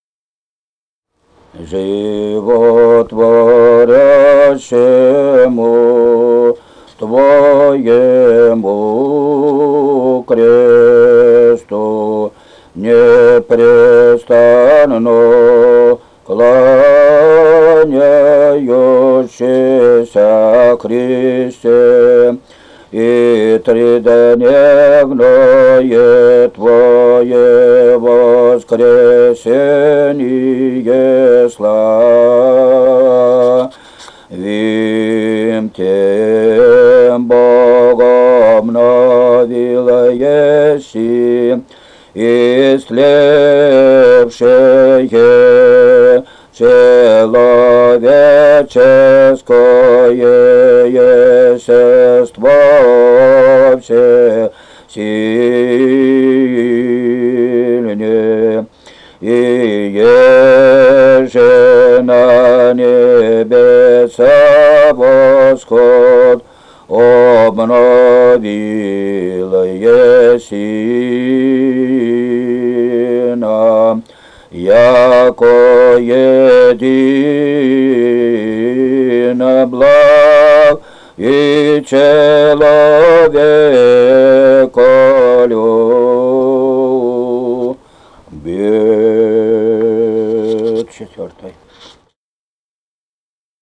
Файл:21 Животворящему Твоему Кресту. Глас 4 И0869-21 Климово.mp3 — Фолк депозитарий